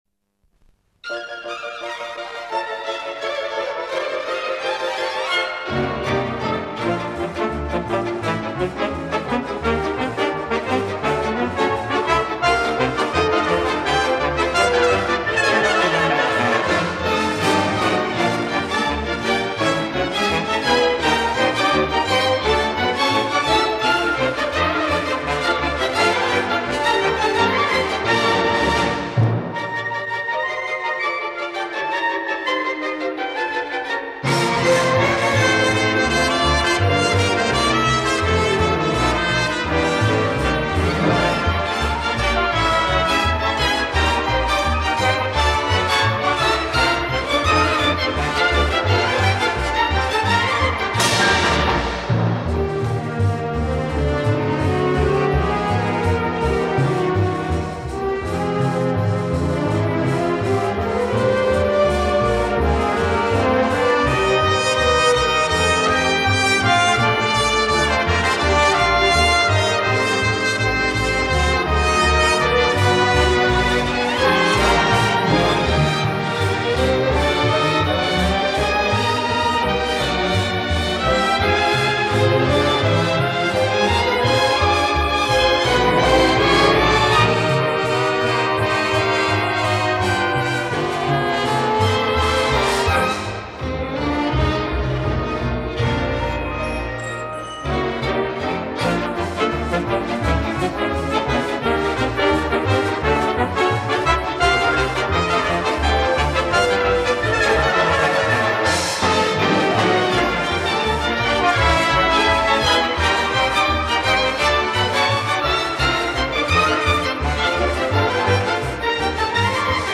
Новые поступления СТАРОГО РАДИО. Инструментальная музыка советских композиторов (ч. 7-я).